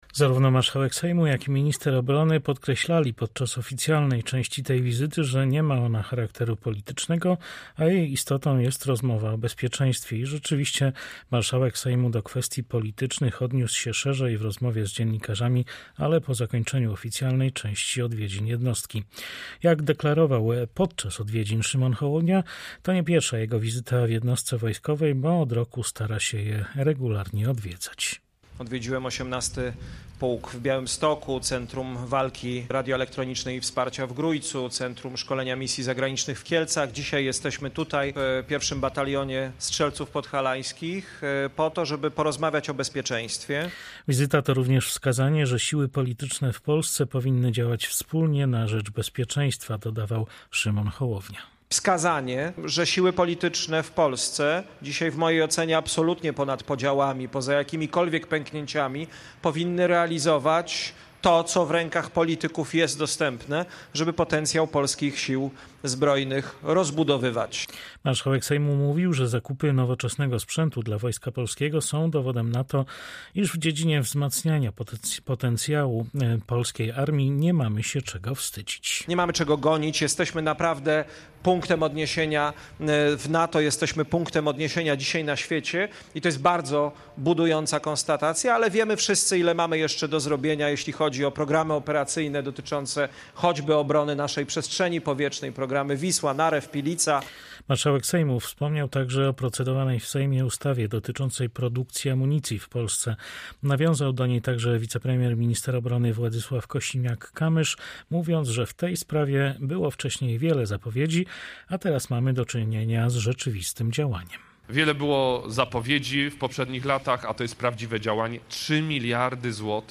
Do kwestii politycznych Marszałek Sejmu odniósł się po zakończeniu oficjalnej części wizyty, na krótkim spotkaniu z dziennikarzami.
– Siły polityczne w Polsce powinny działać wspólnie na rzecz bezpieczeństwa – mówił podczas wizyty marszałek Sejmu.